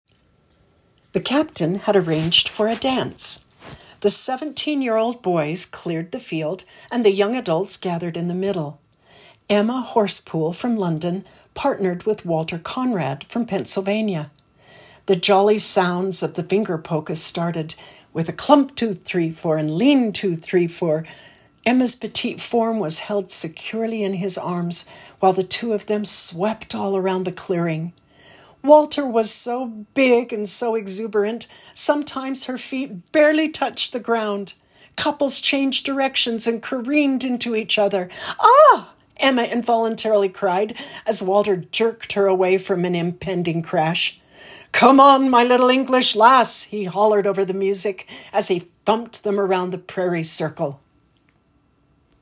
Book Audio Sample